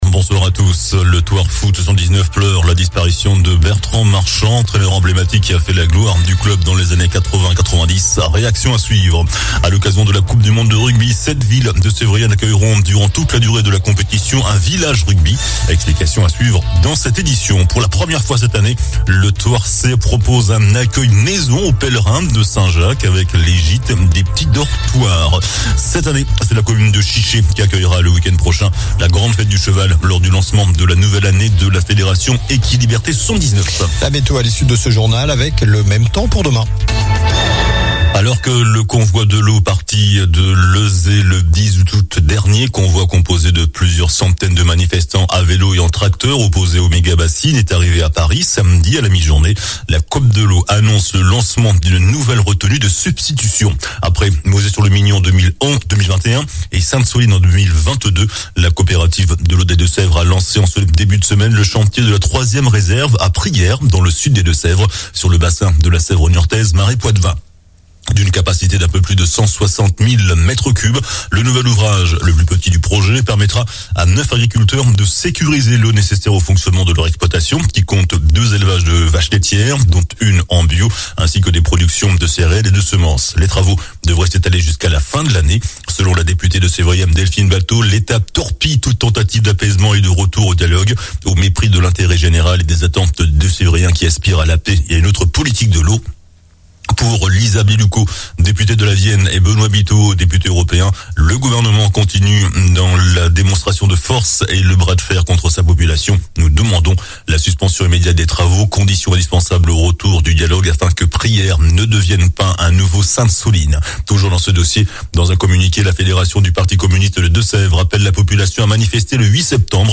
JOURNAL DU LUNDI 28 AOÛT ( SOIR )